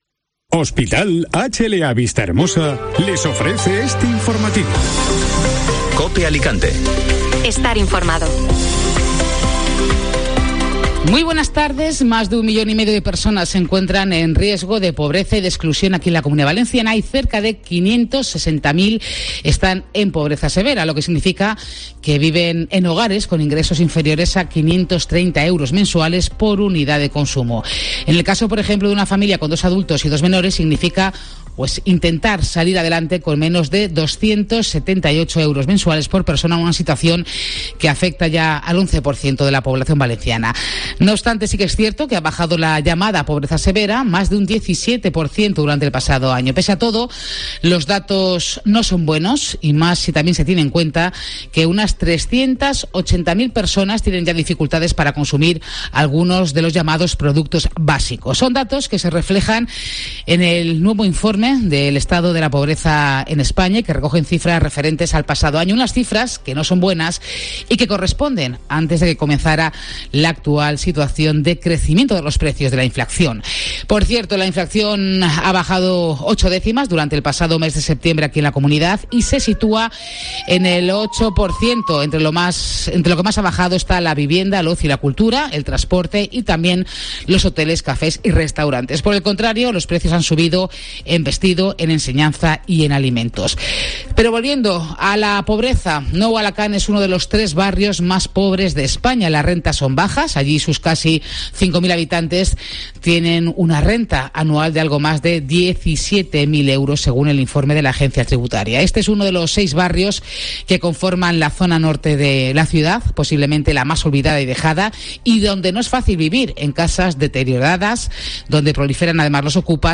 Informativo Mediodía COPE Alicante (Viernes 14 de octubre)